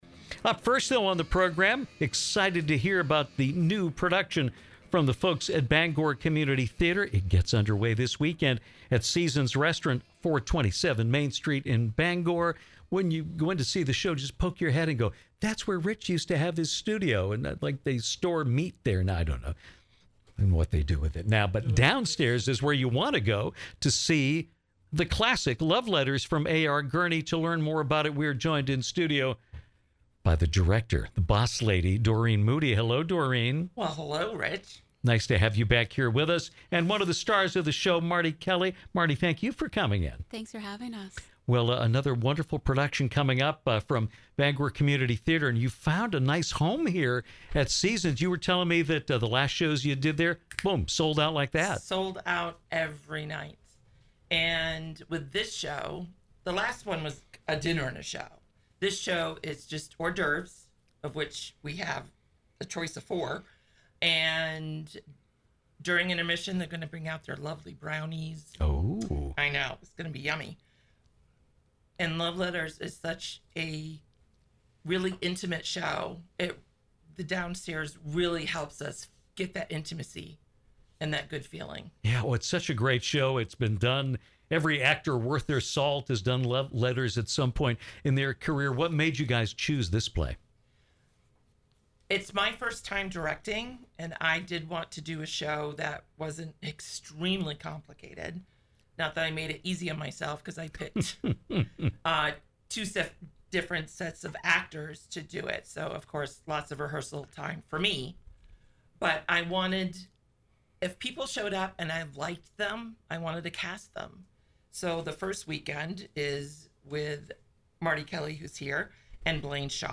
joined us in studio to talk about their upcoming production of LOVE LETTERS, playing the next two weekends at Seasons on Main Street in Bangor.